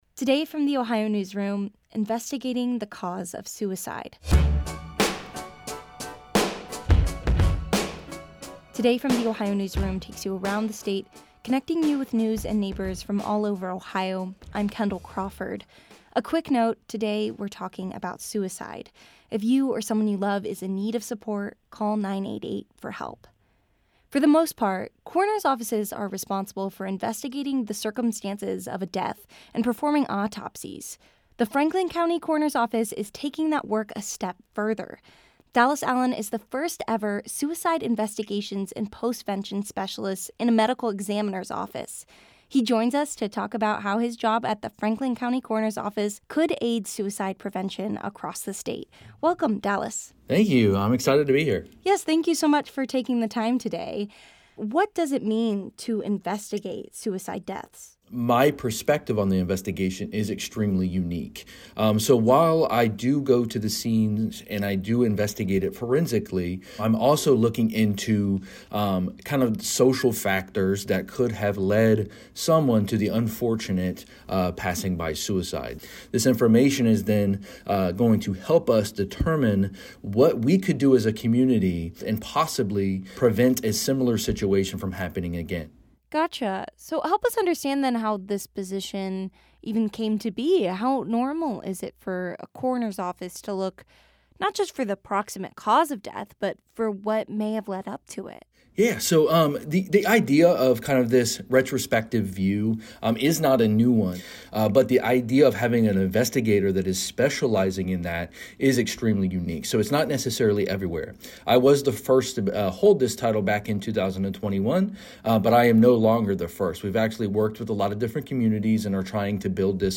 This interview has been edited for brevity and clarity.